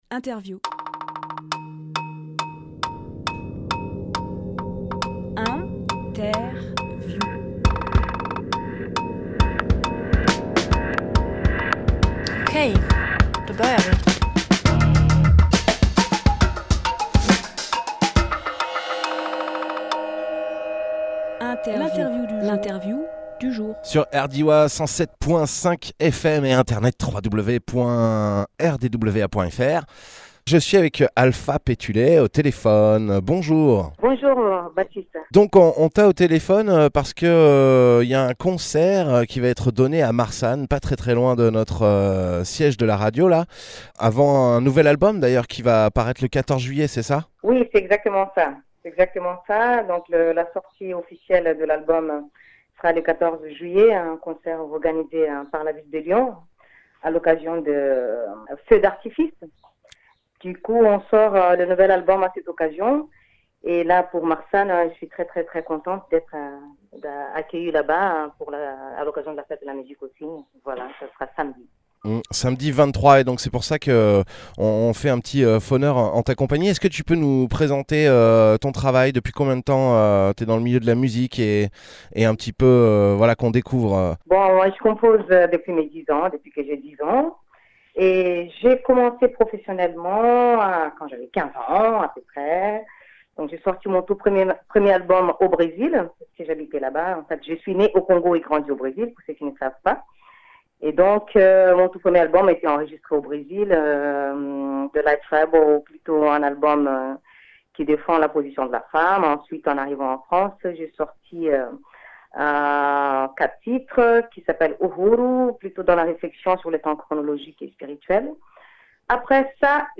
Emission - Interview
Lieu : Téléphone